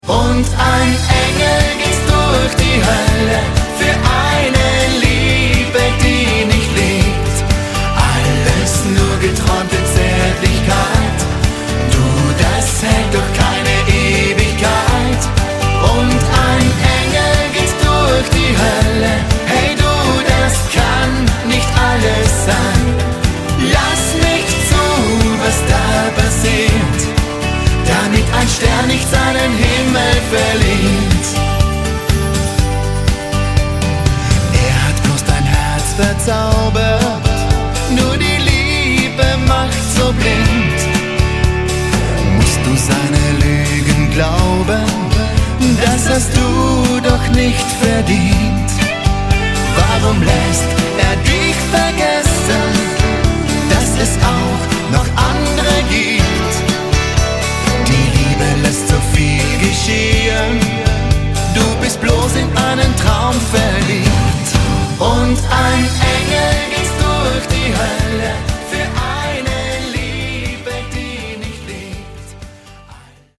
MODERN